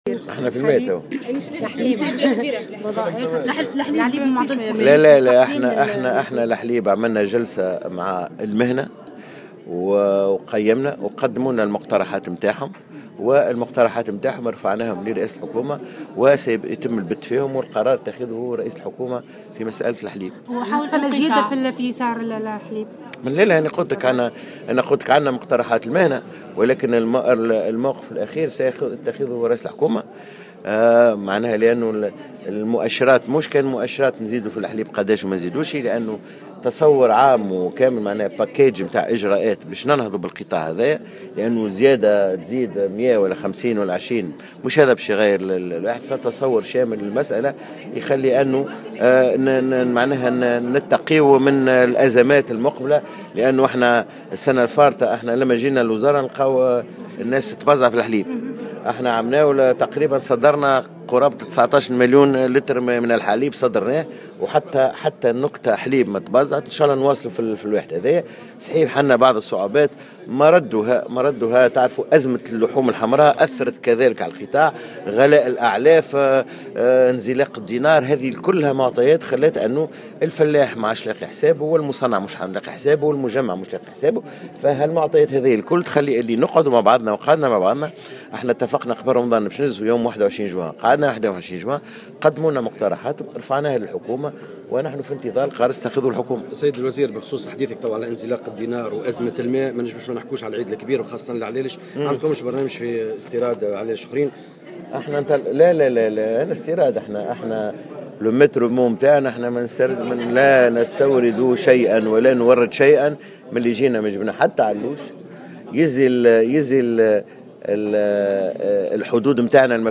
قال وزير الفلاحة سمير بالطيب في تصريح لمراسلة الجوهرة "اف ام" اليوم الأربعاء إن الزيادة في أسعار الحليب لا تتم إلا بقرار من رئيس الحكومة .